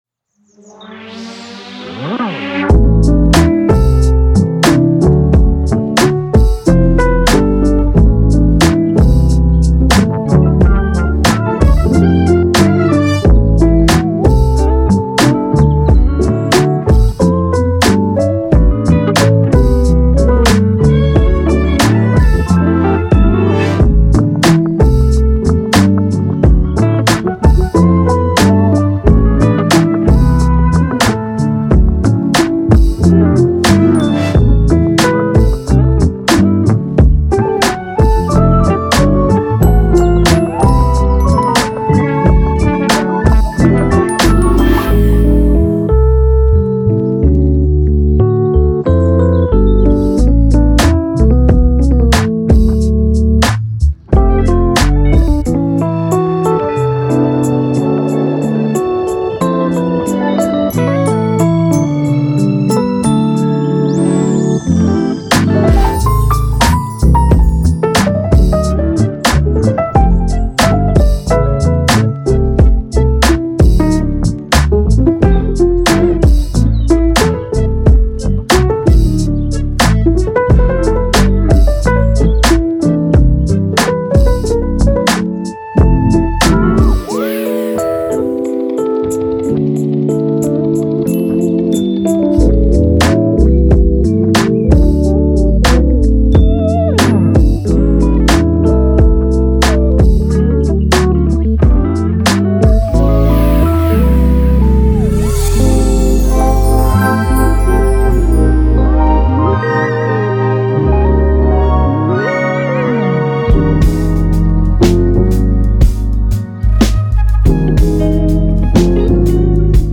trompette
sax alto
flûte